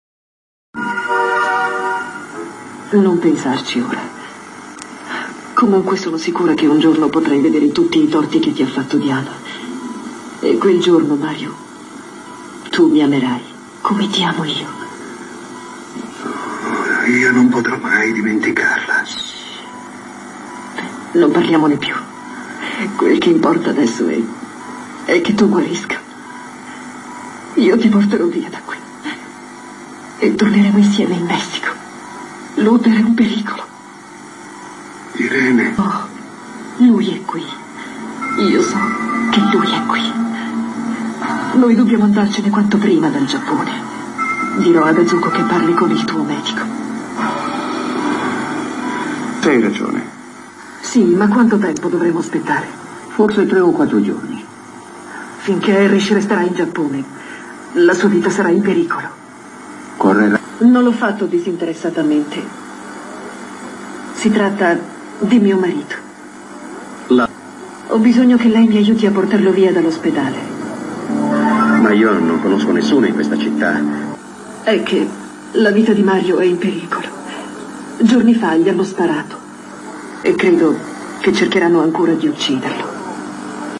SOAP OPERA E TELENOVELAS